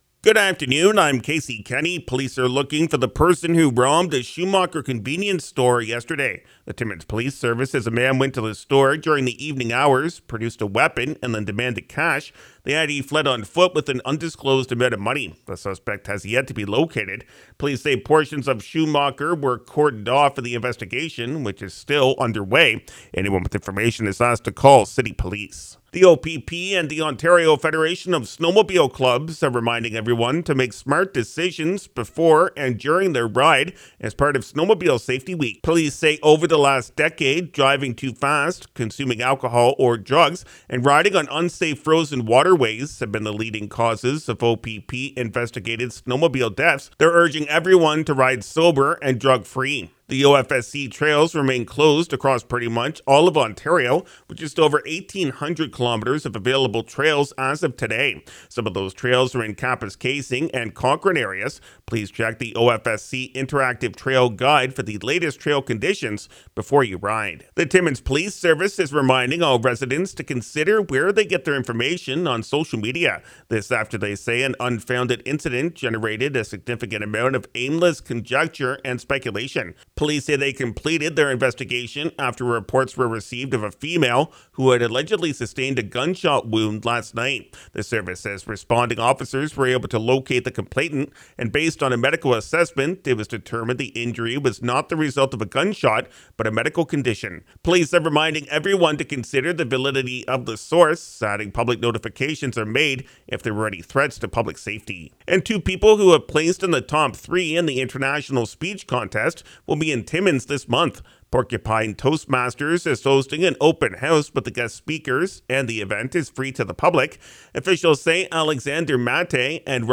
5:00pm Country 93.1 News – Tue., Jan. 16, 2024